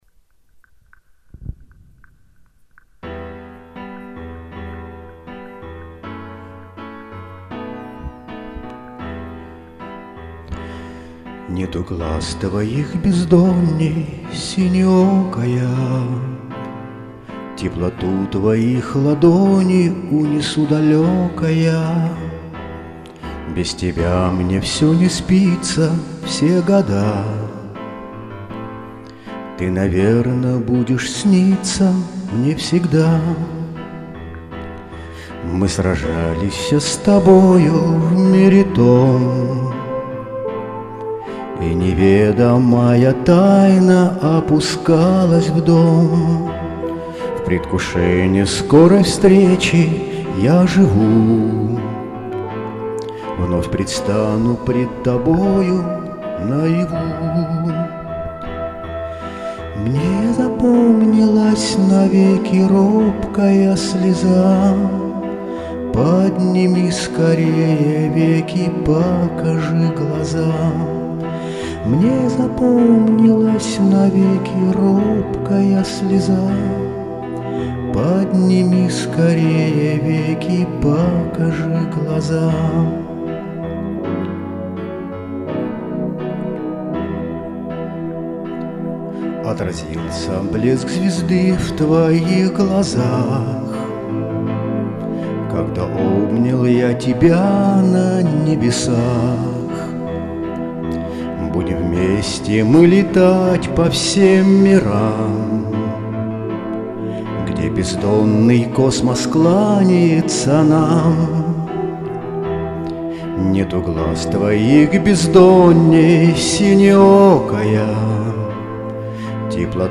Песня на стихи «Безсмертное…»